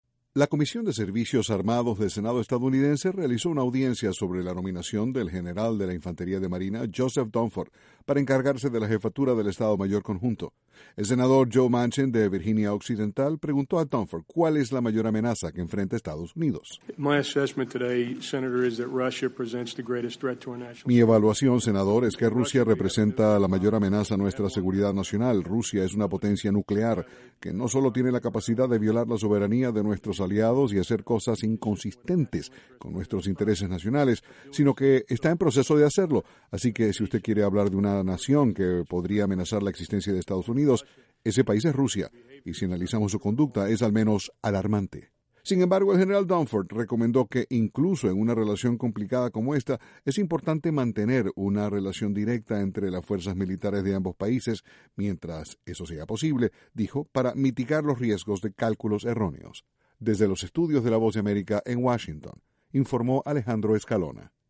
Para un general nominado por Barack Obama al puesto militar mas alto del pais, Rusia es la mayor amenaza que enfrenta Estados Unidos. Desde la Voz de America, Washington